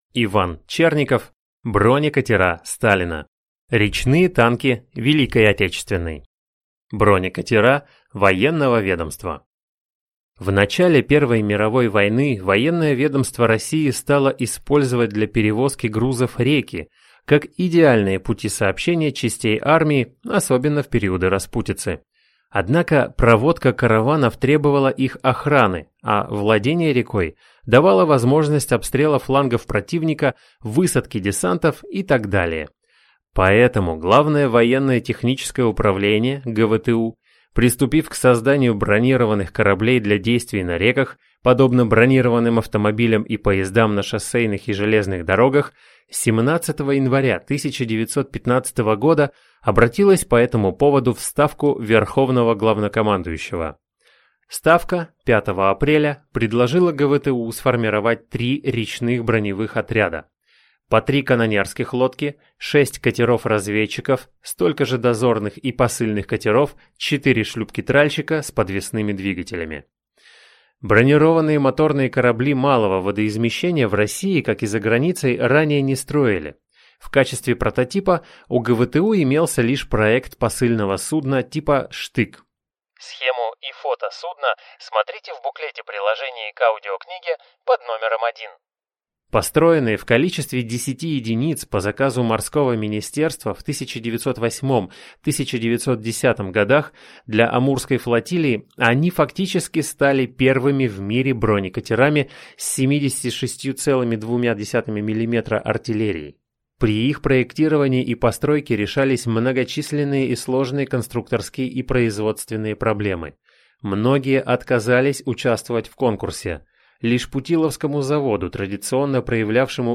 Аудиокнига Бронекатера Сталина. «Речные танки» Великой Отечественной | Библиотека аудиокниг